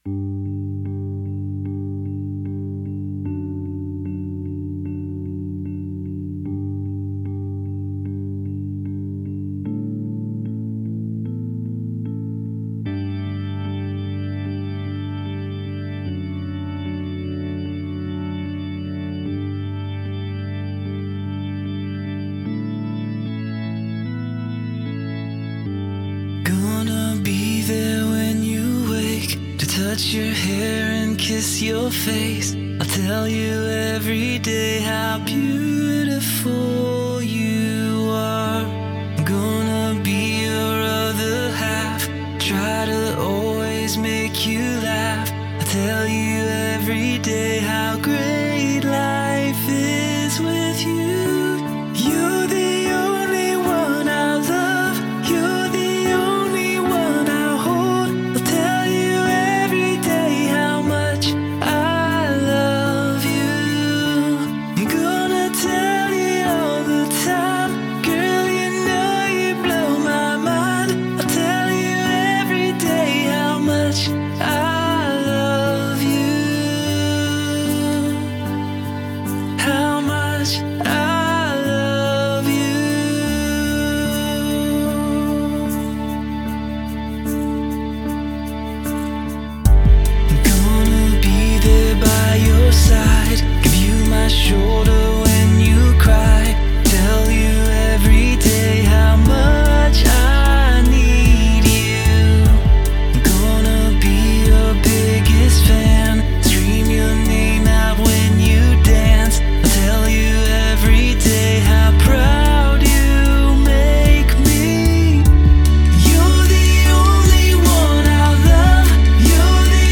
песня
282 просмотра 154 прослушивания 21 скачиваний BPM: 150